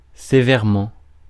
Ääntäminen
Ääntäminen France: IPA: [se.vɛʁ.mɑ̃] Haettu sana löytyi näillä lähdekielillä: ranska Käännöksiä ei löytynyt valitulle kohdekielelle.